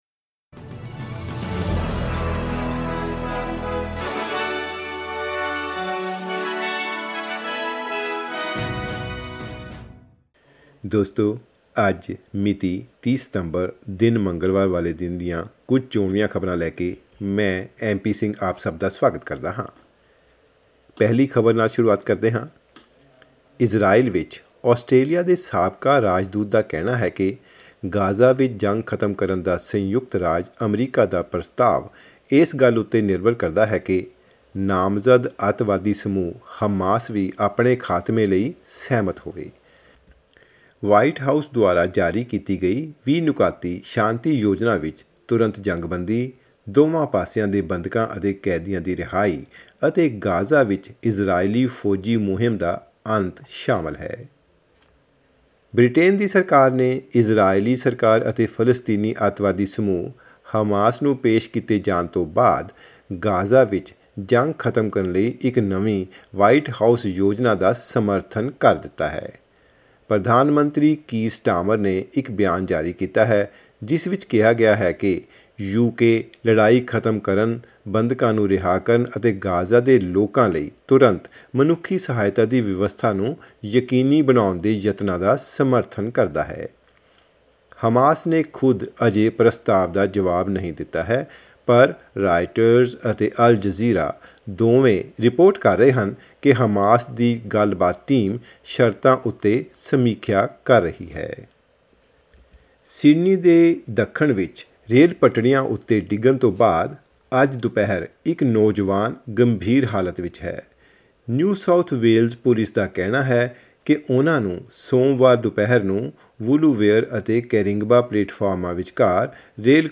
ਖਬਰਨਾਮਾ: ਓਪਟਸ ਆਉਟੇਜ - ਸਰਕਾਰ ਨੇ ਸੁਤੰਤਰ ਜਾਂਚ ਦੀ ਮੰਗ ਕੀਤੀ ਰੱਦ